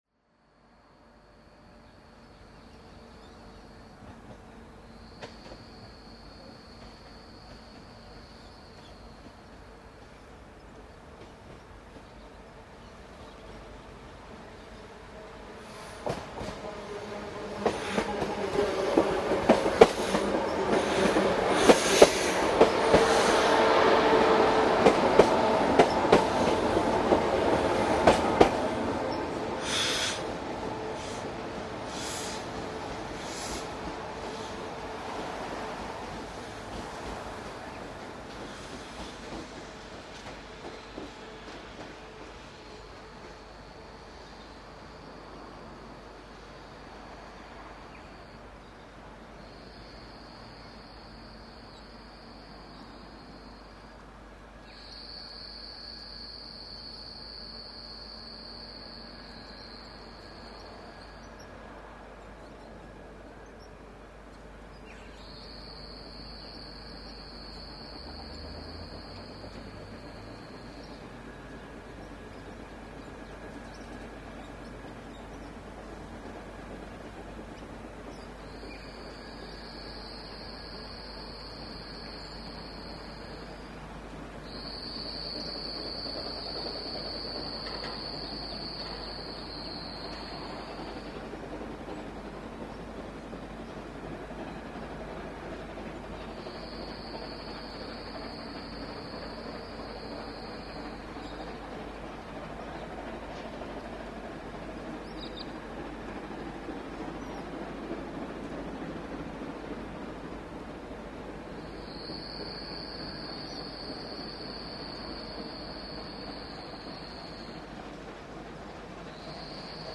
The previous month saw Brown Bomber C17 974 doing the honours, again on the Sherwood Loop.  This time, we were booked to ride the 11.30 am service, so on the way there, took the opportunity to get a lineside recording of the 10.00 am train at Tennyson.